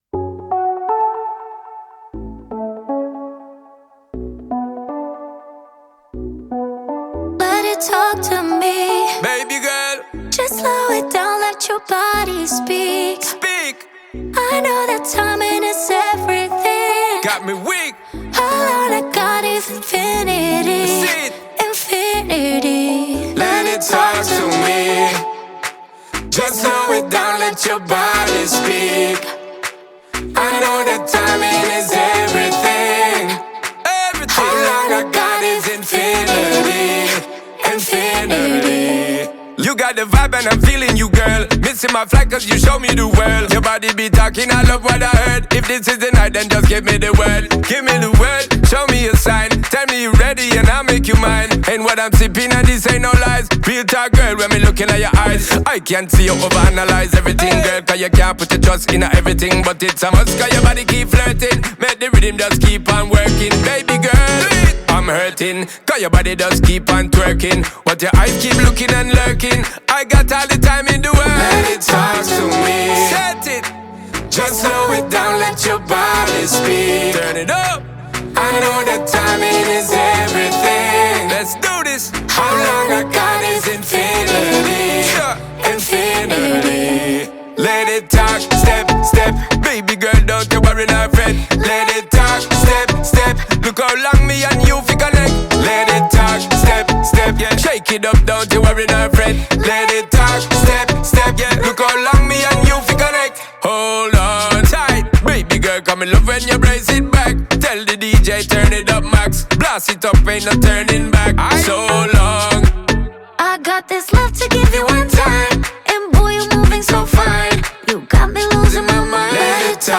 creating an infectious dance anthem.